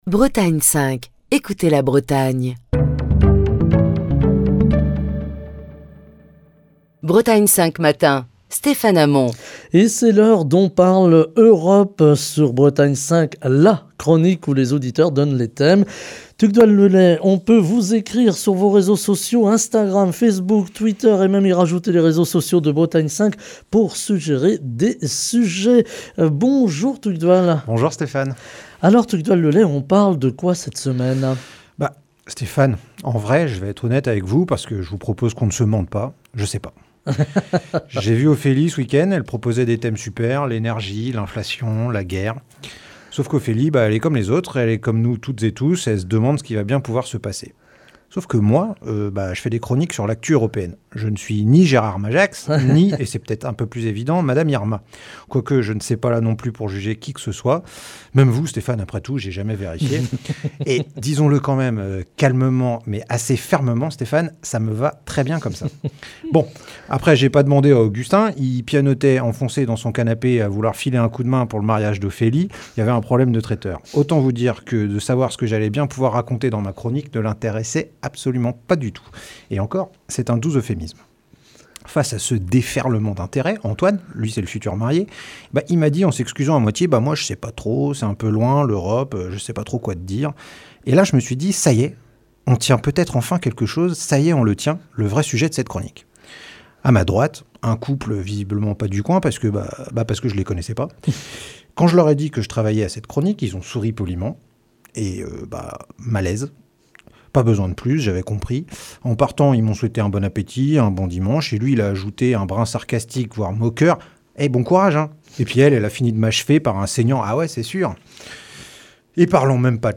Chronique du 31 janvier 2023.